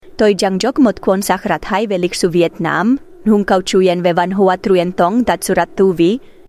ElevenLabs_Vietnamese.mp3